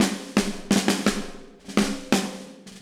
Index of /musicradar/80s-heat-samples/85bpm
AM_MiliSnareC_85-02.wav